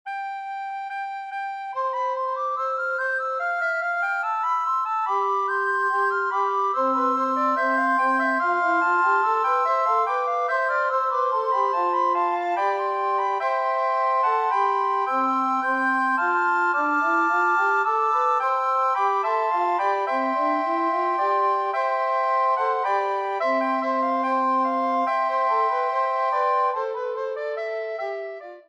S A T B
Each piece (except, curiously, the first!) begins with the standard canzon motif of long-short-short (the so-called dactyl pattern). The parts sometimes engage in a call-and-response with each other, and sometimes play together.